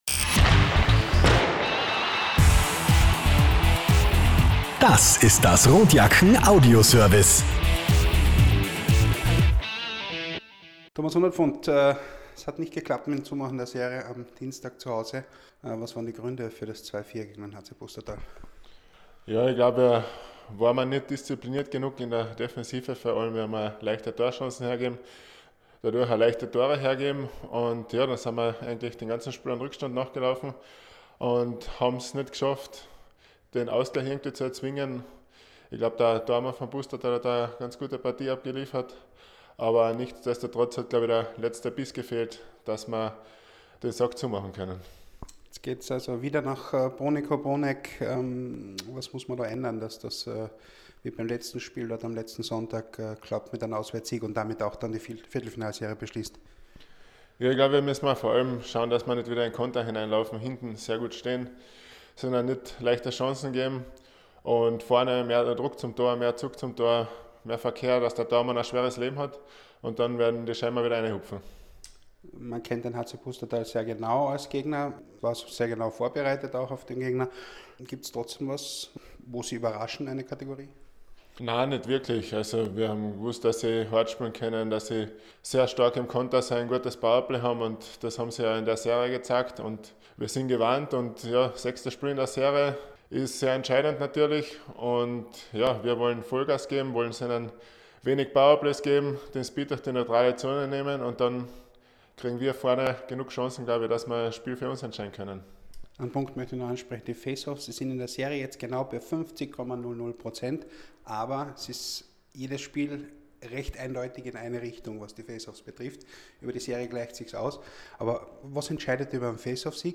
Pre-Game-Kommentar: